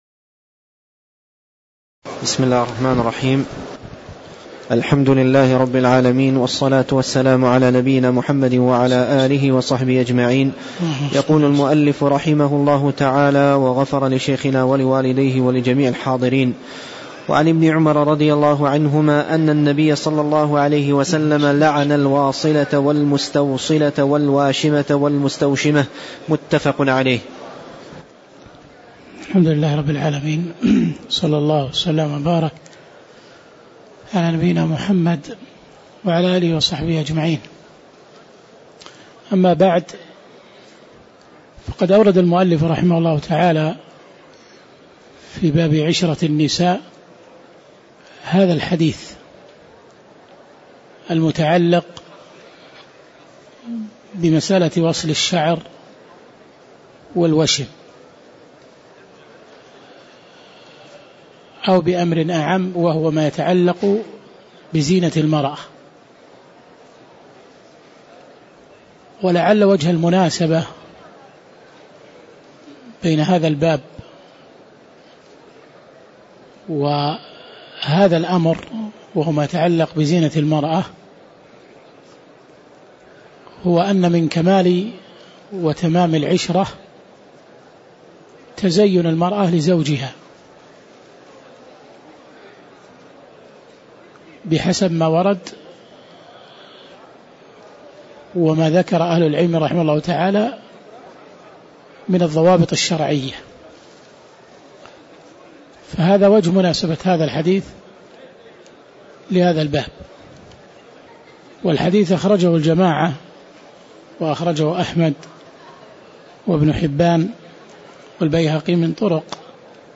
تاريخ النشر ٢٠ جمادى الآخرة ١٤٣٧ هـ المكان: المسجد النبوي الشيخ